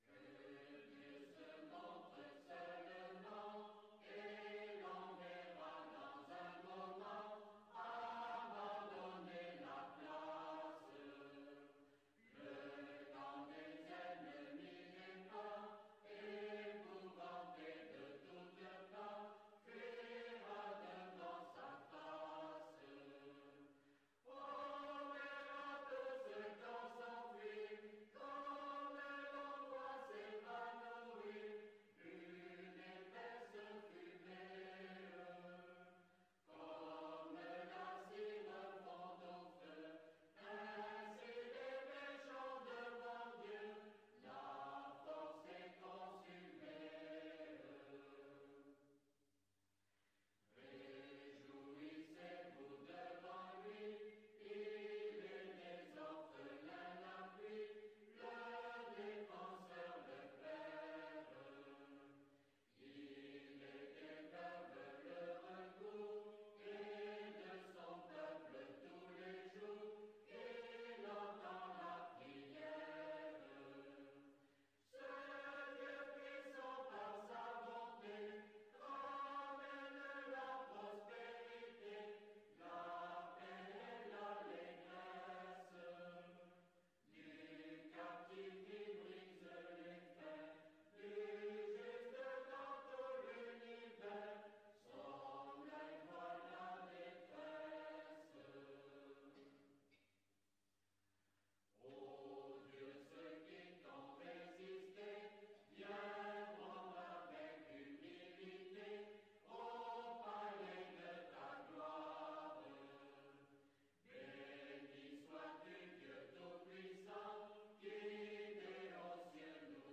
Musique : Chant huguenot - Le Psaume des Batailles (Psaume 68) - 2,90 Mo - 3 mn 22 :